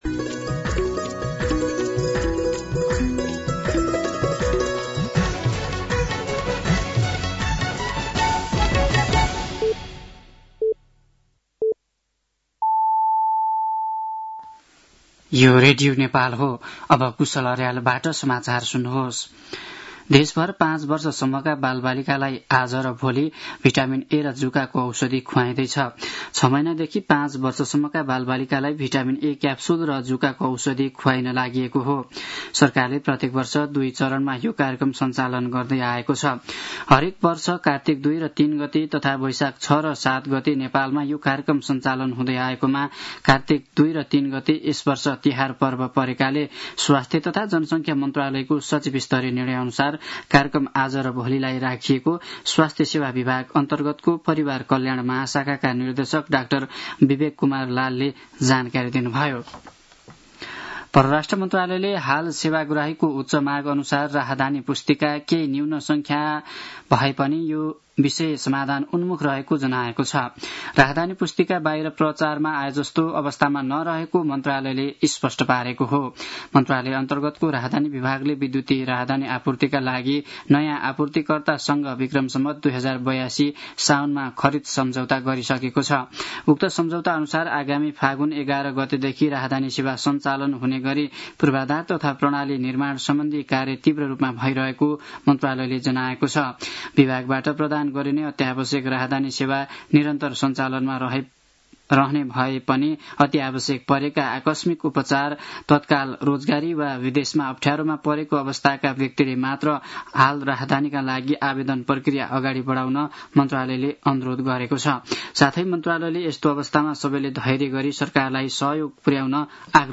साँझ ५ बजेको नेपाली समाचार : २० कार्तिक , २०८२
5-pm-news-7-20.mp3